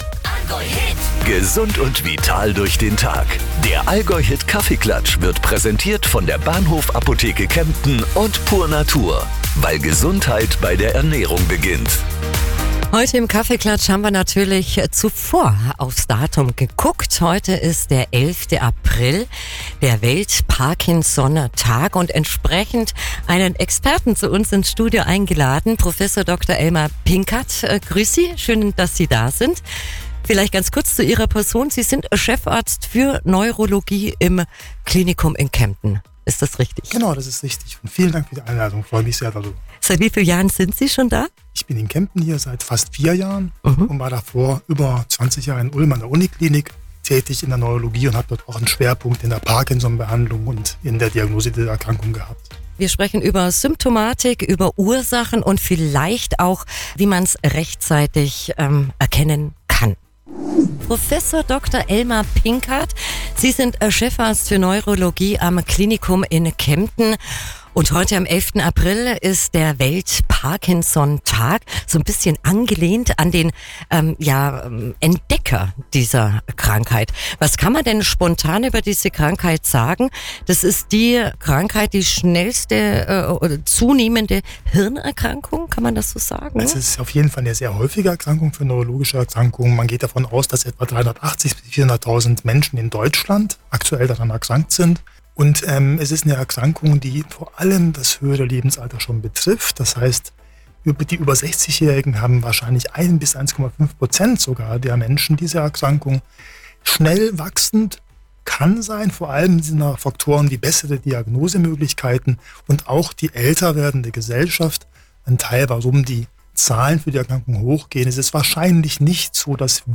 Parkinson Schüttellähmung Symptome Talk